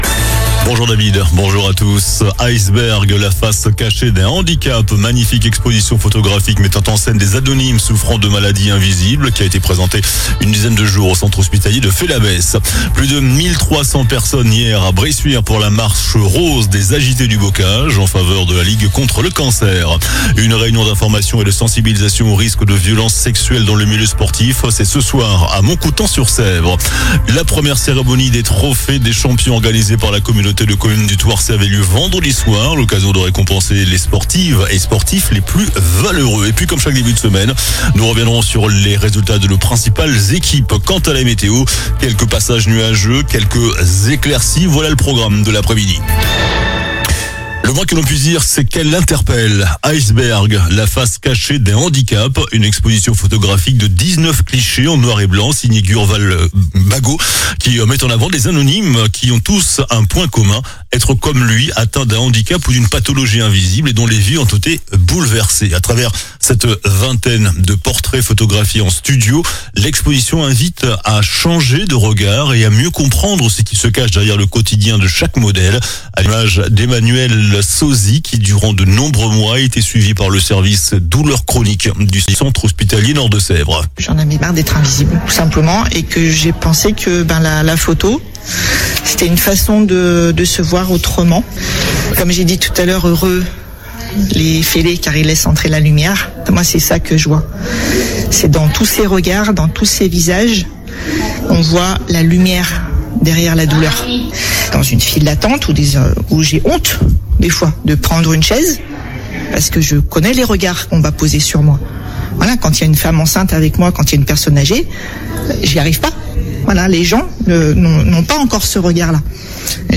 JOURNAL DU LUNDI 20 OCTOBRE ( MIDI )